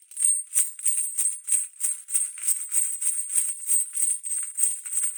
На этой странице собраны звуки кандалов: звон цепей, скрежет металла, тяжелые шаги в оковах.
Звук шагов человека в оковах